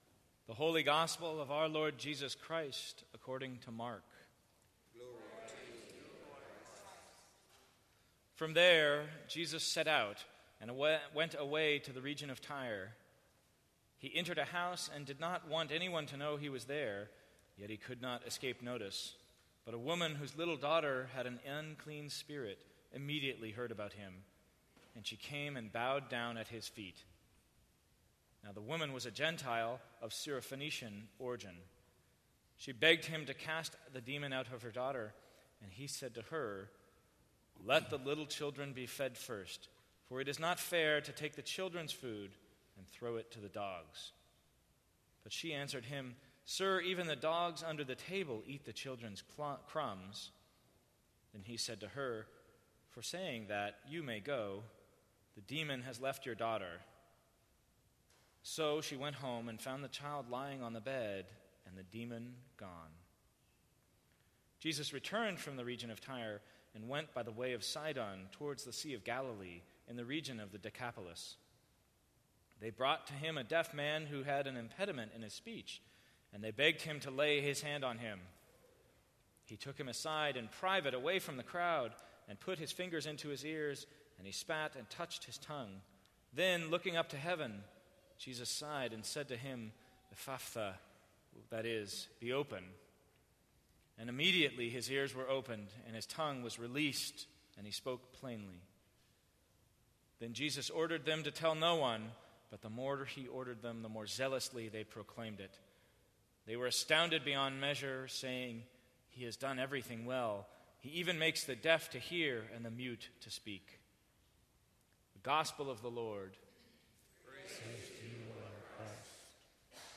Sermons from St. Cross Episcopal Church What if God were one of us?